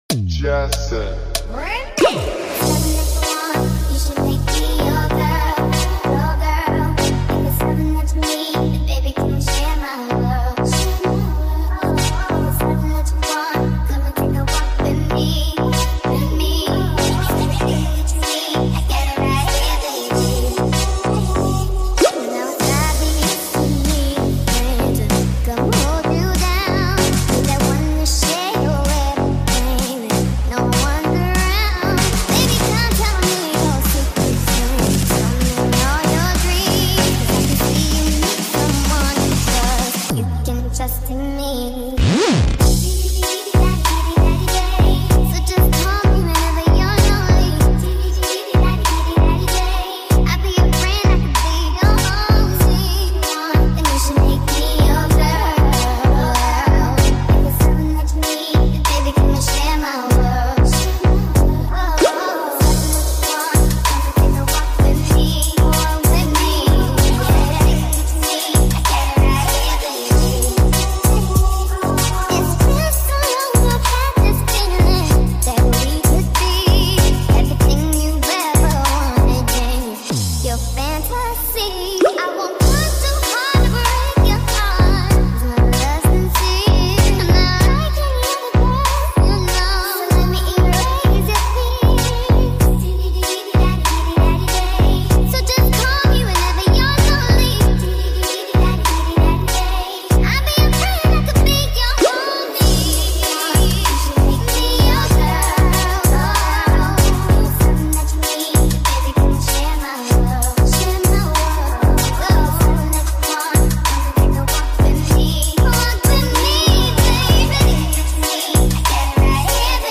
full bass remix